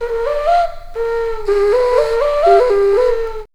AFRIK FLUTE4.wav